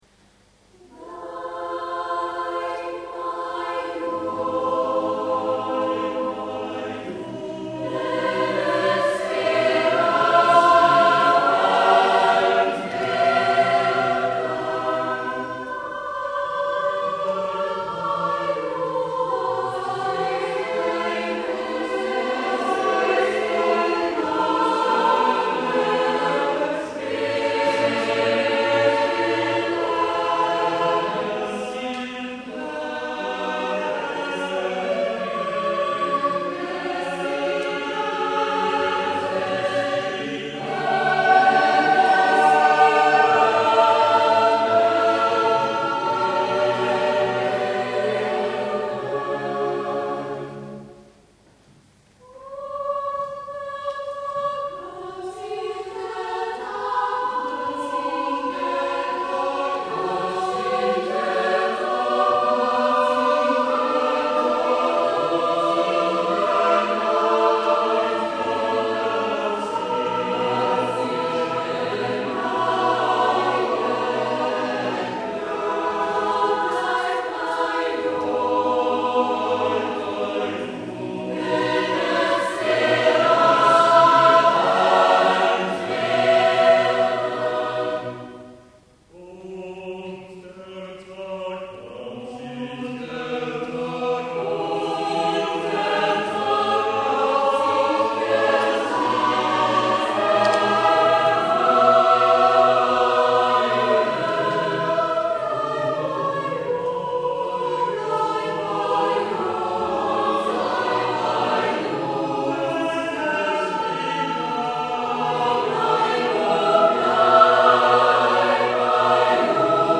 Unter diesem Titel hat am 10. März 2002 der Chor der Pfarre Mauthausen zu einem besinnlichen Konzert geladen.
19.00 Uhr Pfarrkirche Mauthausen
Chor der Pfarre Mauthausen musica viva
Chor a cappella